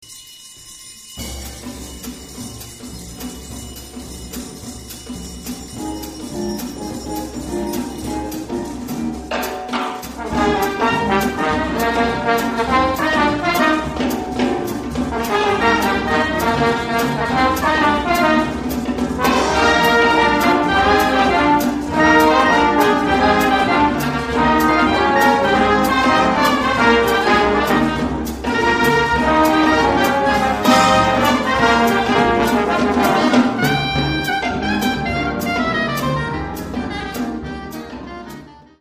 BIG BAND ARRANGEMENTS FROM
Calypso with solos for almost everyone but especially for Soprano. Line-up: 4 trumpets, 4 trombones, 2 Altos, Tenor, Tenor on Soprano, 2 Flutes, Piano, Guitar, Bass, Drums, Leadsheet.